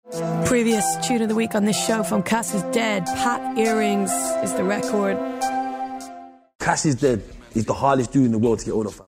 読み方
カシスデッド
Annie Mac、Charlie Slothの発音